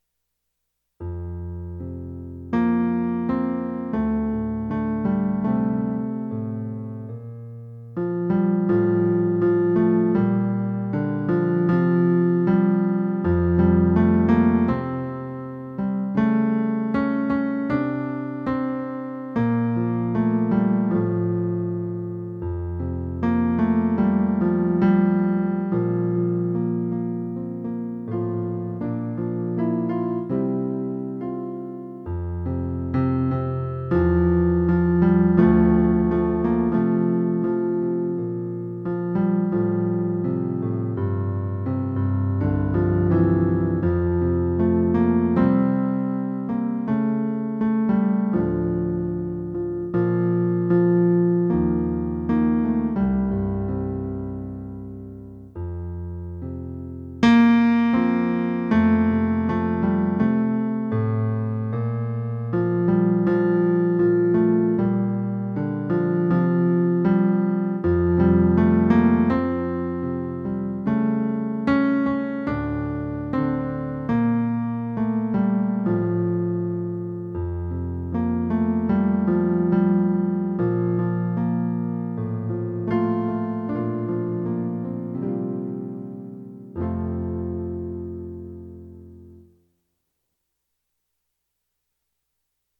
Set Your Heart on the Higher Gifts - Men